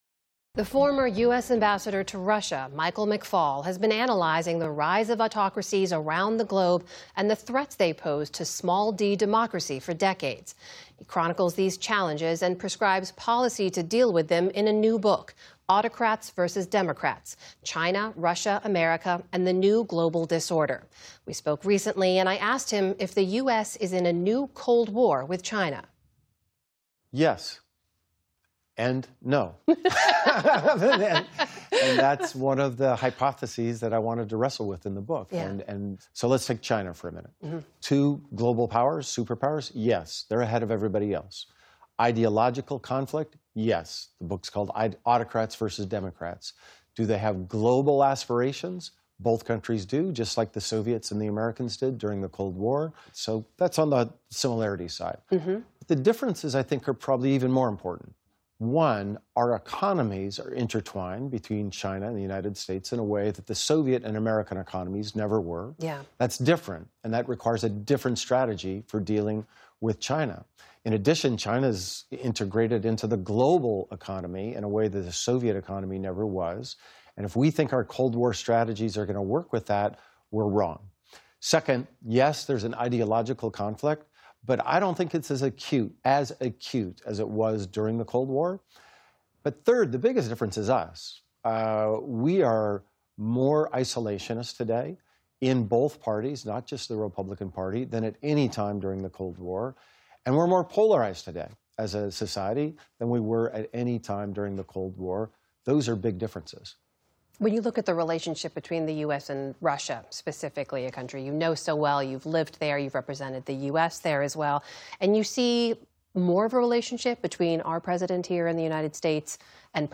The former U.S. ambassador to Russia, Michael McFaul, has been analyzing the rise of autocracies and the threats they pose to democracy for decades. Amna Nawaz sat down with McFaul to discuss his new book, “Autocrats vs. Democrats: China, Russia, America, and the New Global Disorder.”